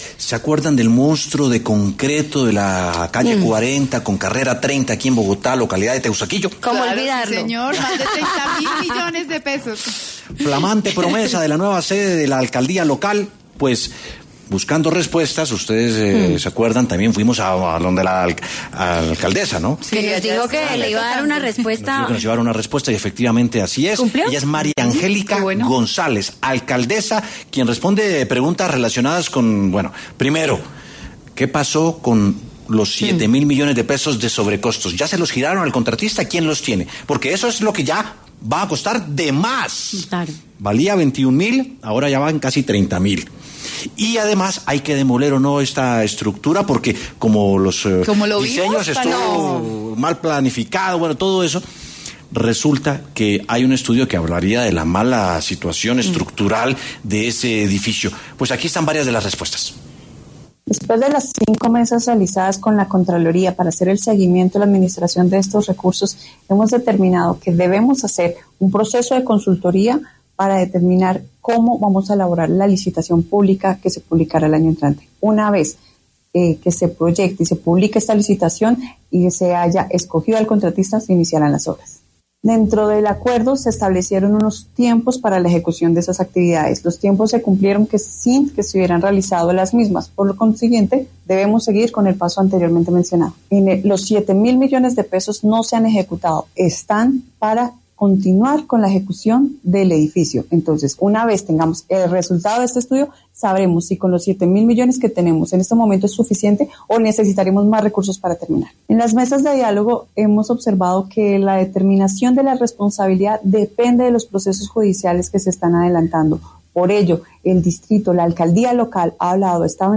La alcaldesa de Teusaquillo habló con W Sin Carreta sobre el mal estado en el que está la obra de la nueva sede de la Alcaldía de esa localidad.